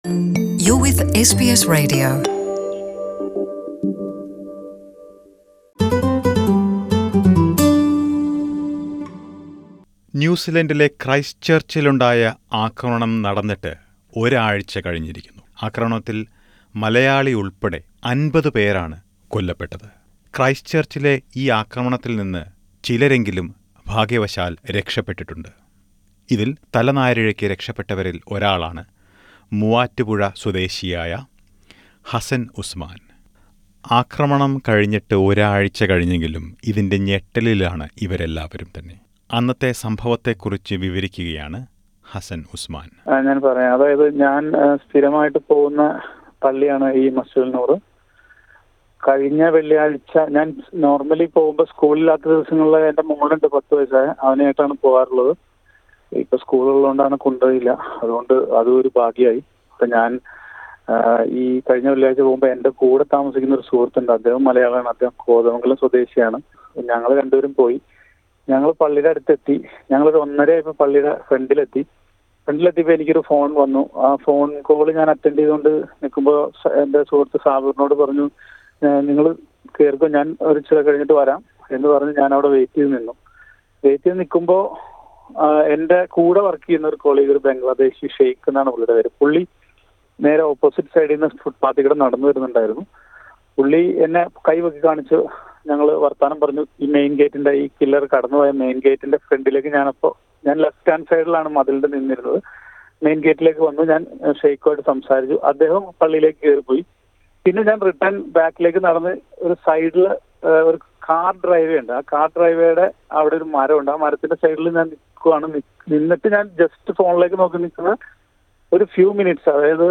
During this deeply disturbing time, New Zealand prime minister Jacinda Arden is being praised for her leadership that is helping the families affected gather strength and face this devastating incident. Some Malayalees in Christchurch and in Australia, including a worshipper who narrowly escaped the shooting speaks to SBS Malayalam about the role Jacinda Arden is playing in overcoming the impact of the attack.